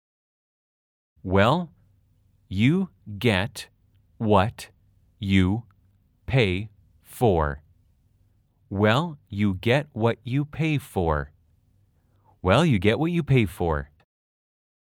/ 웨엘 / 유 개앳 웟유 / 페이 포어 /
아주 천천히-천천히-빠르게 3회 반복 연습하세요.